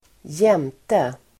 Uttal: [²j'em:te]